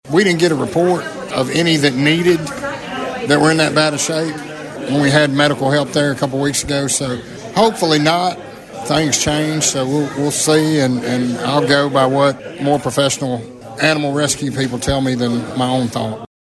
Sheriff McDade speaks about the possibility of euthanasia…